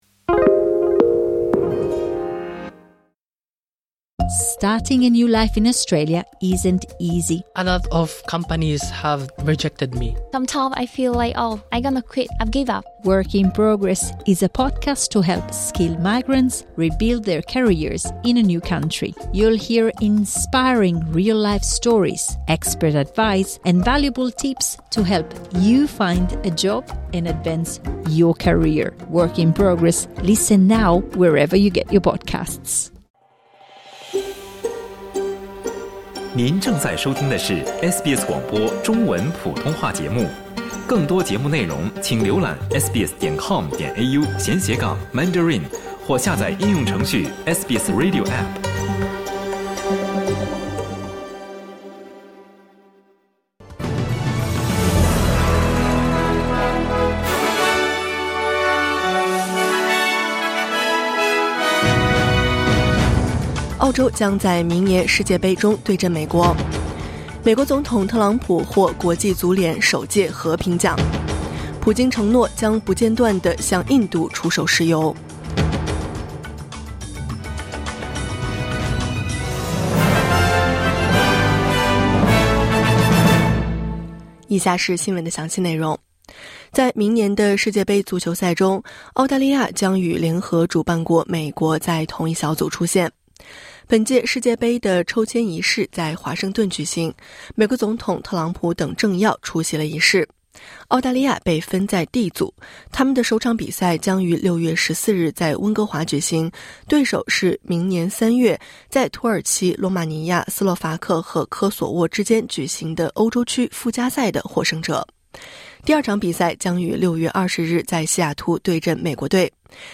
SBS早新闻（2025年12月6日）